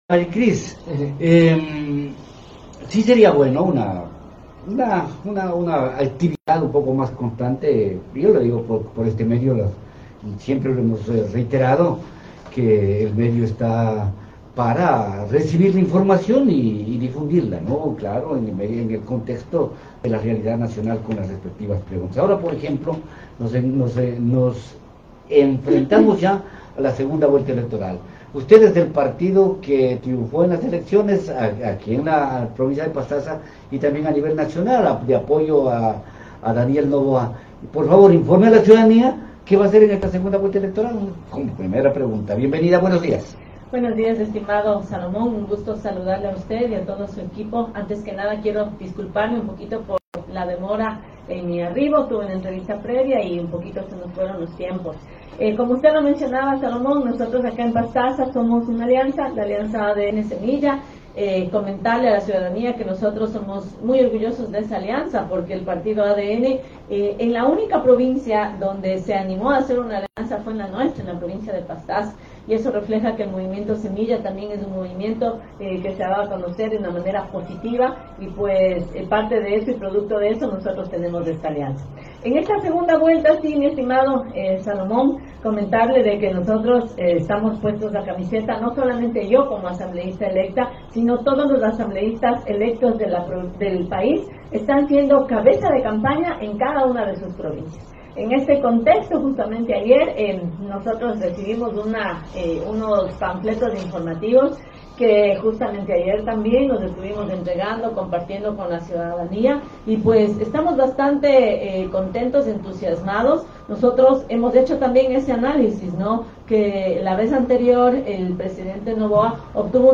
En efecto, en una entrevista en Nina Radio de Puyo el 27 de marzo de 2025, María Acuña, expresó que liderará la campaña política que efectuarán a nivel provincial con el propósito de ganar en el balotaje de segunda vuelta a la candidata Correísta, Luisa González.